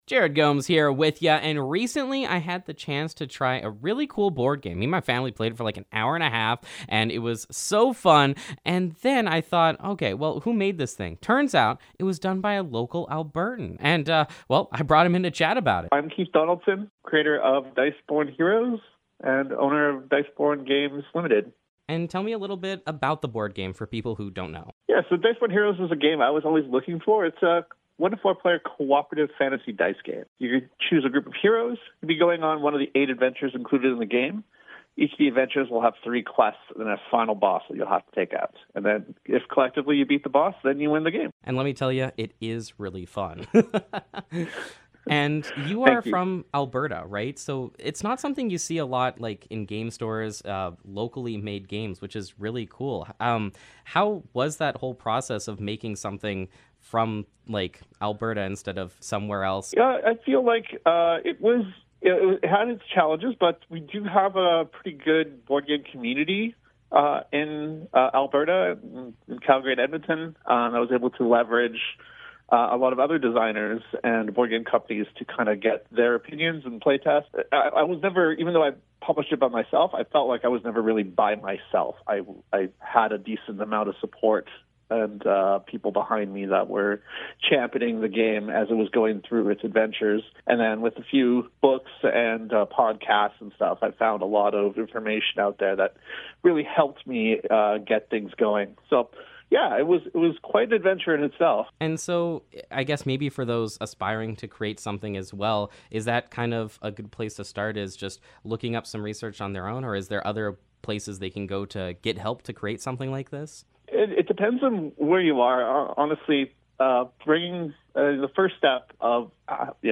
Diceborn Heroes Interview
diceborn-heroes-interview-final.mp3